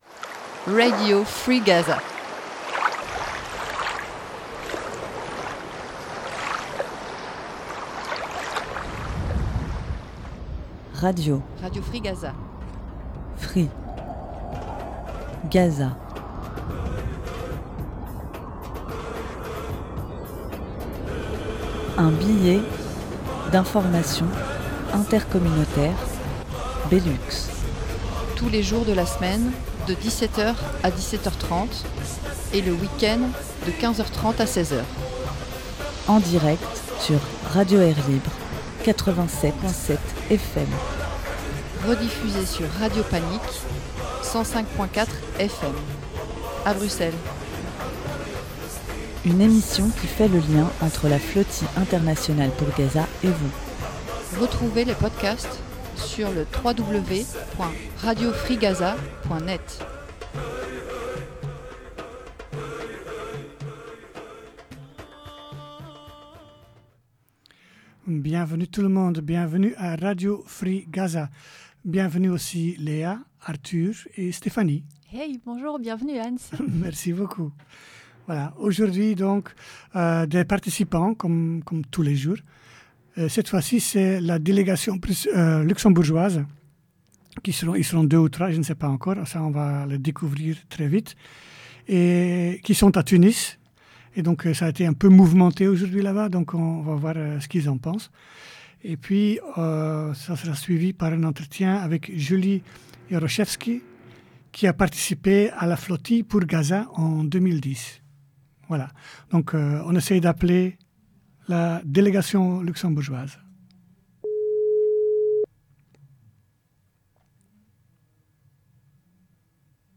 Nous avons commencé l’émission avec deux membres de la délégation luxembourgeoise de la Flotille Mondiale du Sumud.